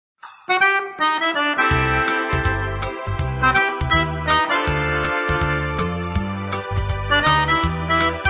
Fisitalia Standard Piano Accordion 37.45 S
Piccolo
3745s_piccolo.mp3